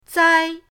zai1.mp3